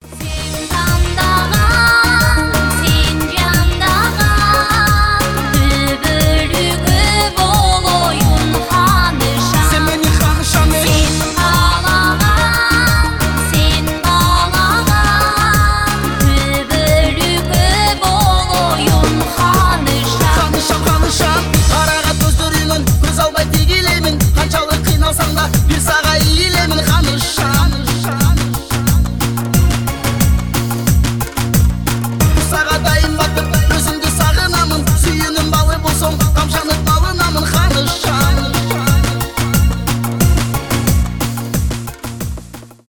дуэт
поп